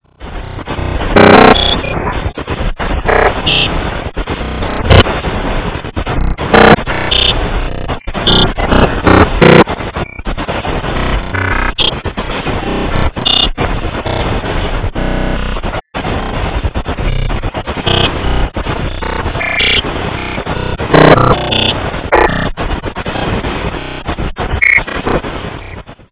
(an early experiment) Pine 9½ inch record back to SS V LP
Pine 9½ inch record back to SS V LP